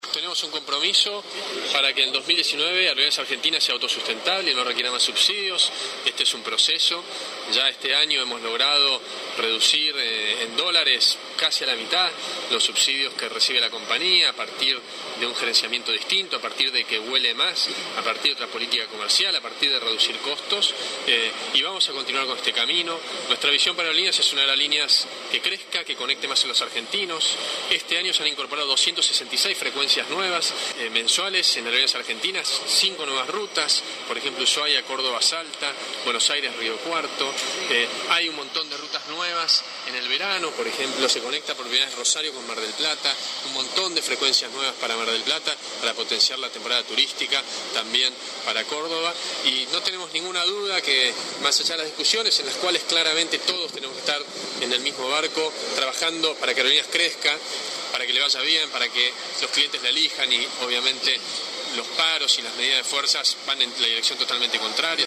En su paso por el Congreso de la Nación-Comisión de Transporte- Guillermo Dietrich fue absolutamente claro y preciso respecto a la potencial llegada de las empresas aéreas low cost.